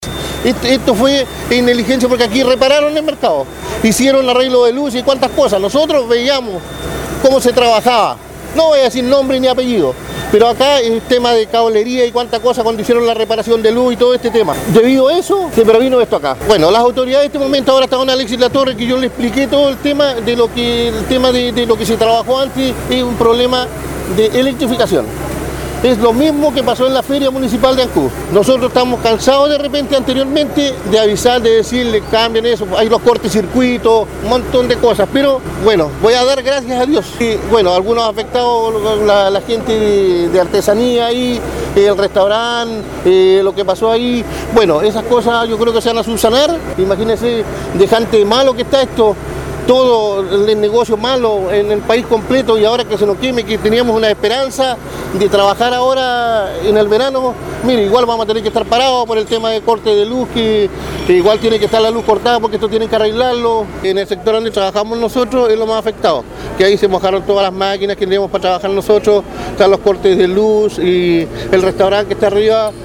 COMERCIANTE.mp3